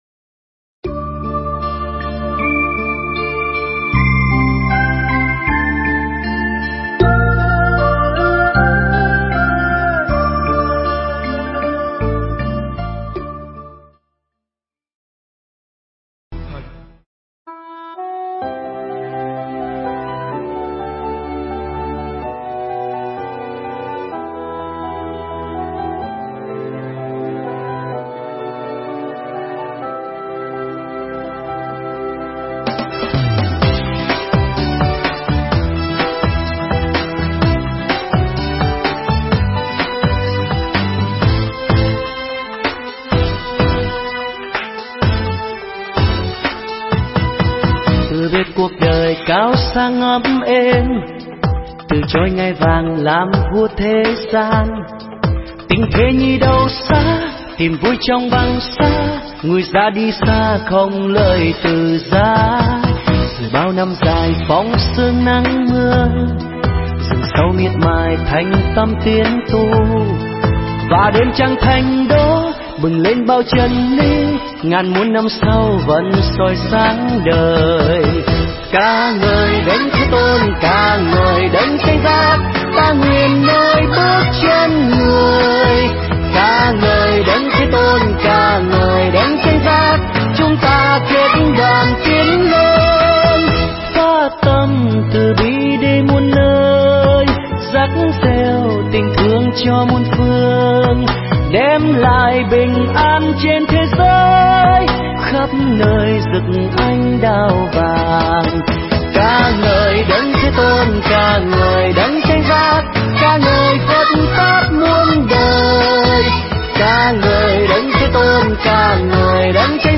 Mp3 thuyết pháp Thái Độ Khoan Dung